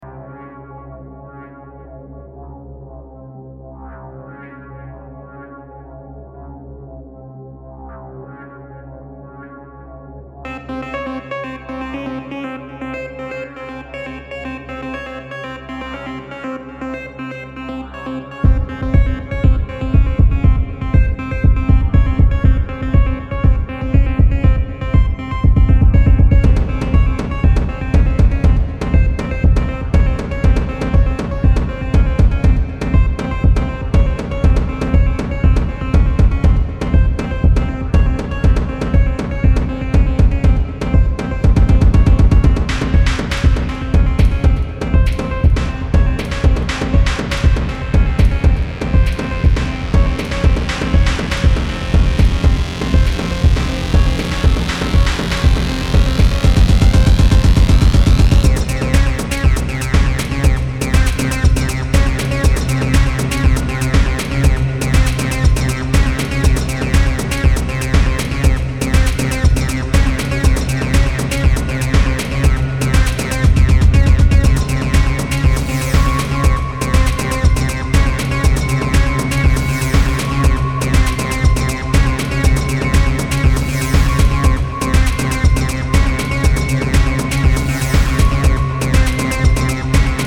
Vocal driven wave cuts
Wave